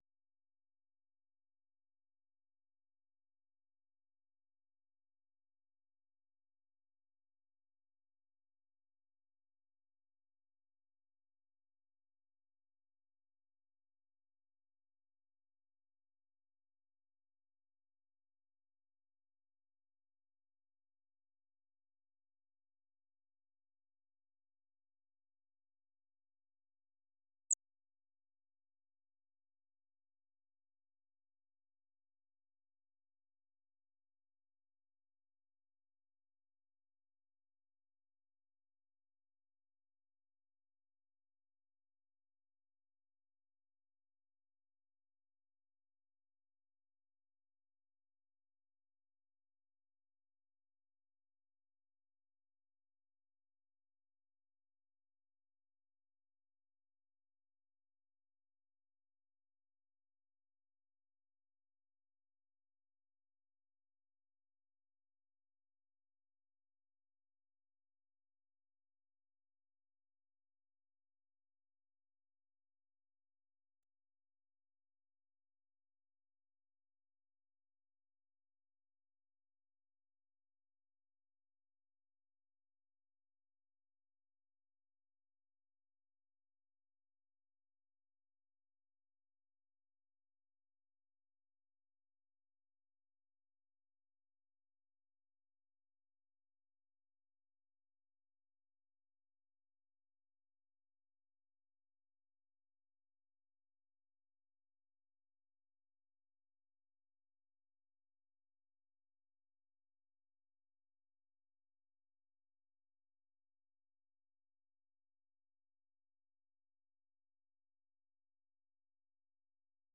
Oferece noticias do dia, informação, analises, desporto, artes, entretenimento, saúde, questões em debate em África. Às sextas em especial um convidado explora vários ângulos de um tema.